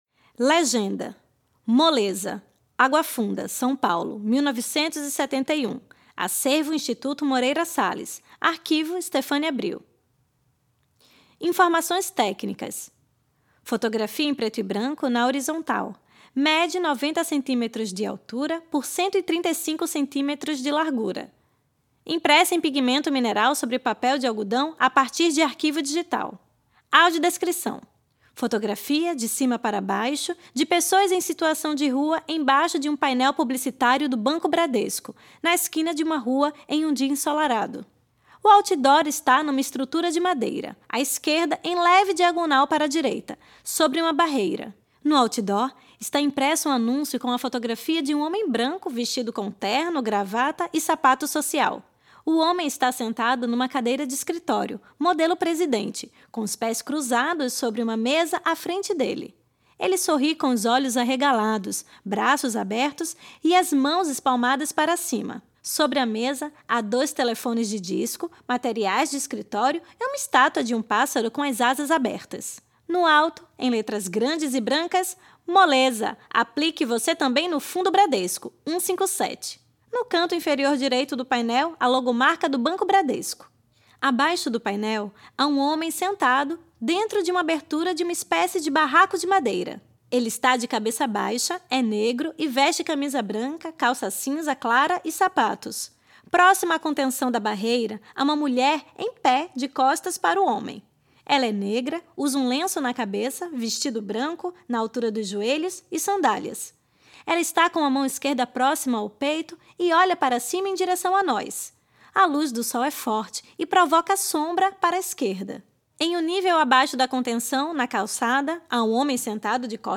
Faixa 3 - Audiodescrição